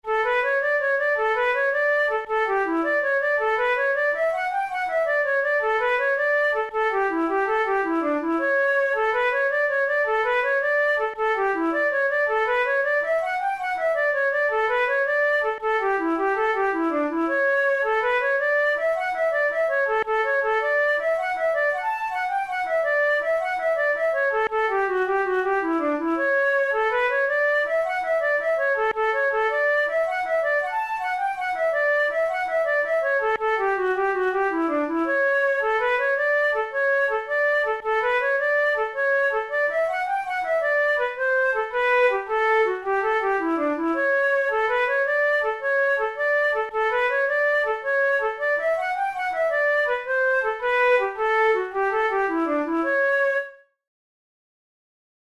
InstrumentationFlute solo
KeyD major
Time signature6/8
Tempo108 BPM
Jigs, Traditional/Folk
Traditional Irish jig